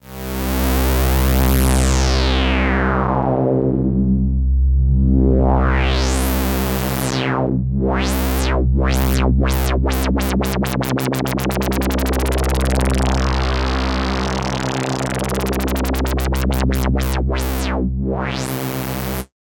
An LFO is applied to the filter of our sound.